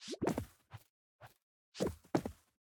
Minecraft Version Minecraft Version 1.21.4 Latest Release | Latest Snapshot 1.21.4 / assets / minecraft / sounds / mob / armadillo / peek.ogg Compare With Compare With Latest Release | Latest Snapshot